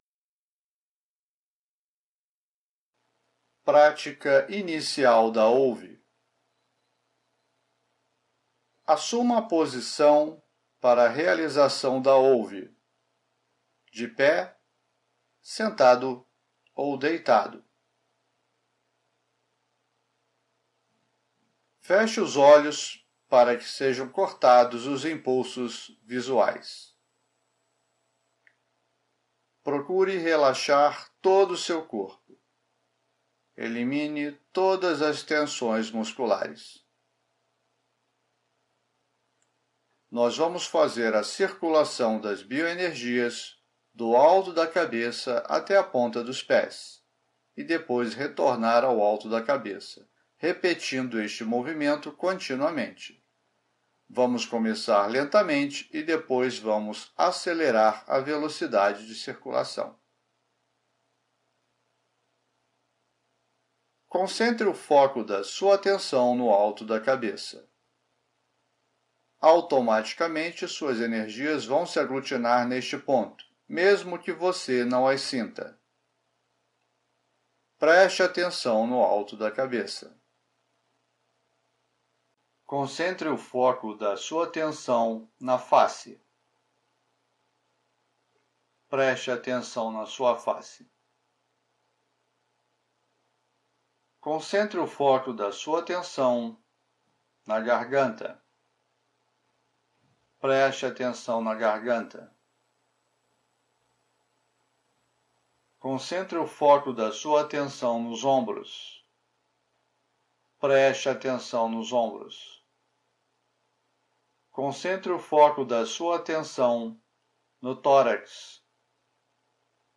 Neste vídeo apresento a prática da OLVE para iniciantes que nunca realizaram a técnica. A condução começa bem lentamente e o direcionamento é contínuo para manter o foco do praticante e evitar desconcentração ou devaneios.